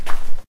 default_dirt_footstep.1.ogg